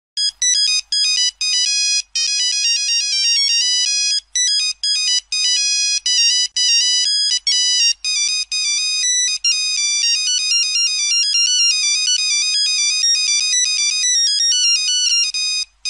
Kategori: Nada dering
nada dering Monophonic klasik